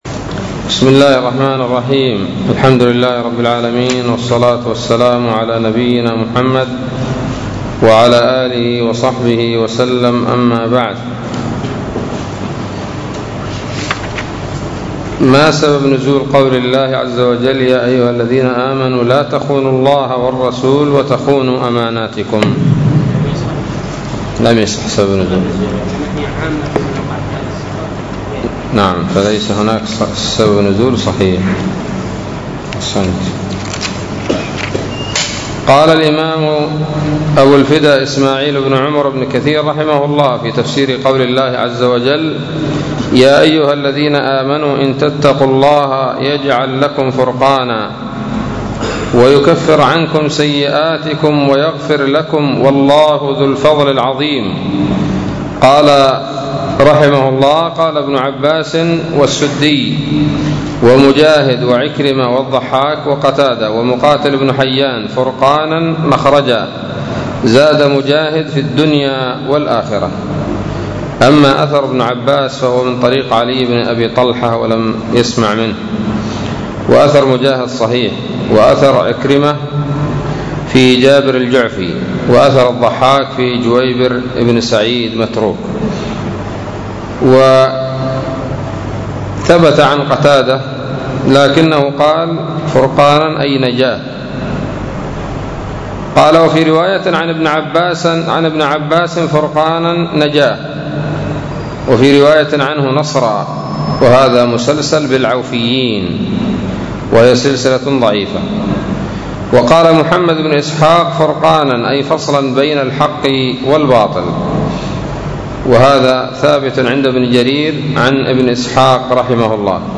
الدرس السادس عشر من سورة الأنفال من تفسير ابن كثير رحمه الله تعالى